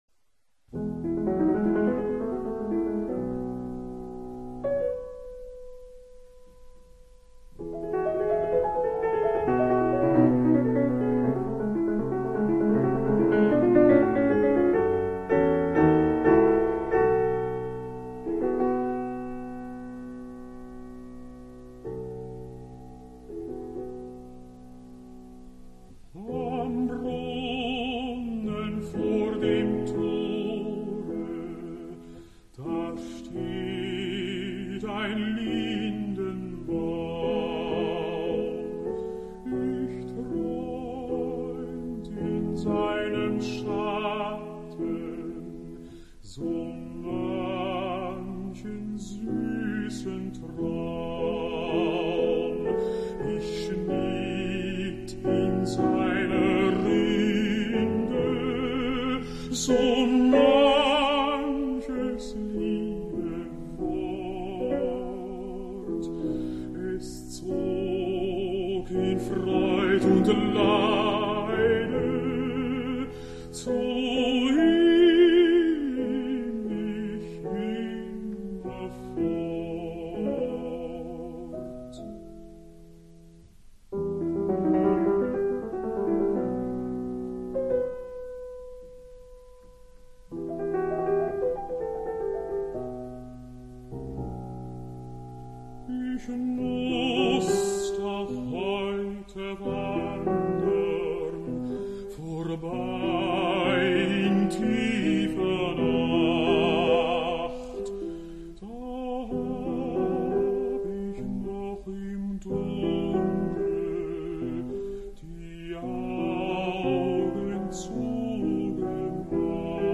Voix d'hommes - Coups de coeur
Baryton - Dietrich Fischer-Dieskau
0414_Winterreise_Voyage_d_hiver_Franz_Schubert_Baryton_Dietrich_Fischer-Dieskau.mp3